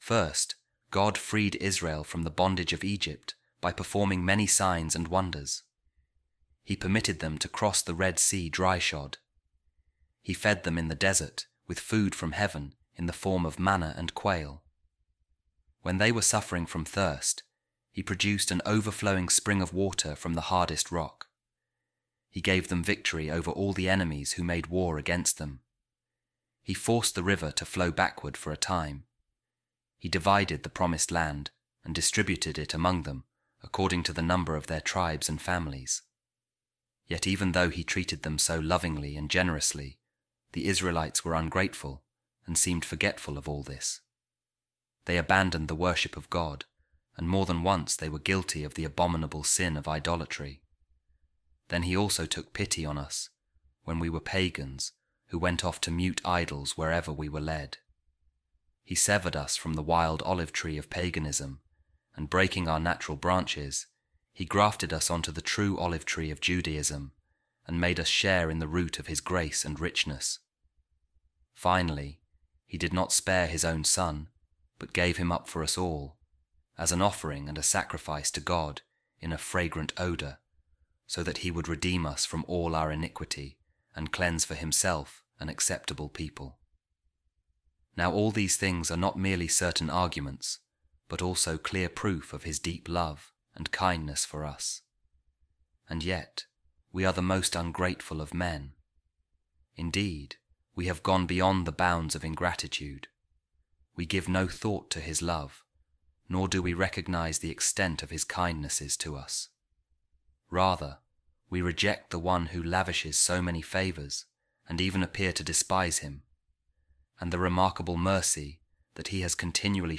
Office Of Readings | Week 3, Friday, Ordinary Time | A Reading From The Commentary Of Saint John Fisher On The Psalms | God’s Wonderful Deeds